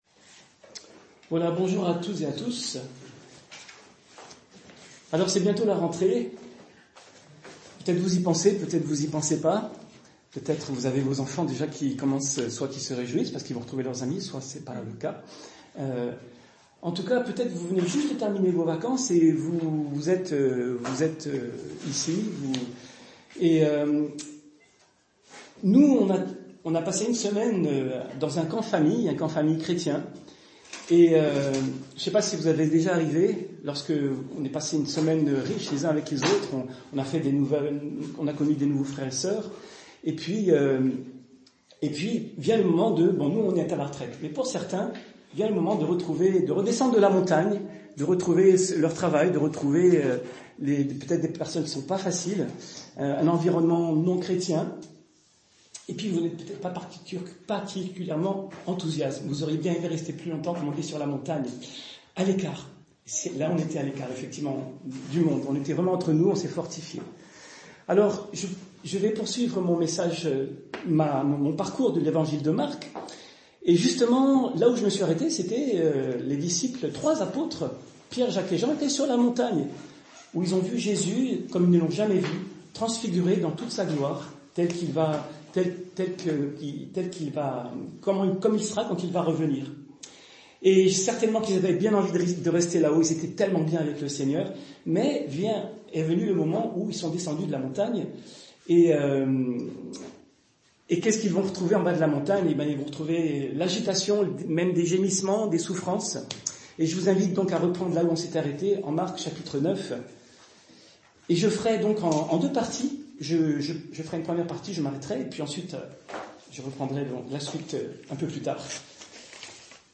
Culte du dimanche 25 août 2024 - EPEF